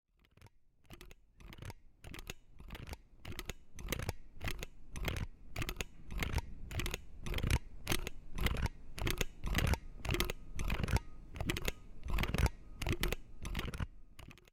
玻璃 陶瓷 " 陶瓷冰激凌碗用金属勺子刮擦 04
描述：用金属勺刮一个陶瓷冰淇淋碗。 用Tascam DR40录制。
Tag: 刮掉 金属勺 金属 陶瓷